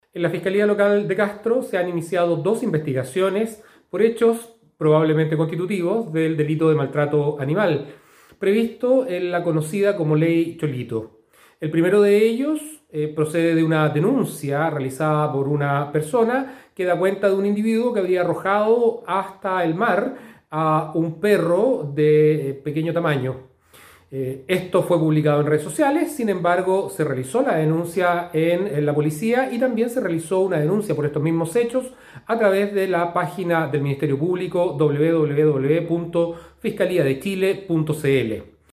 Se trata de dos repudiables hechos que la comunidad ha podido conocer a través de videos que se han viralizado por medio de redes sociales en los primeros días de este año 2021, señaló el fiscal jefe de Castro Enrique Canales.
06-FISCAL-CANALES-MALTRATO-ANIMAL.mp3